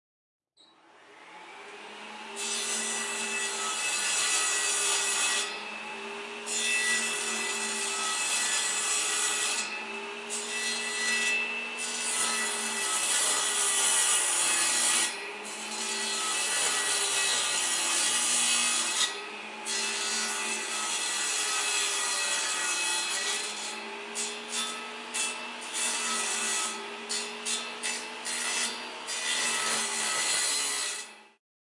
水轮
描述：这是伯明翰Sarehole磨坊的水轮声，
标签： 磨机 研磨 玉米 面粉
声道立体声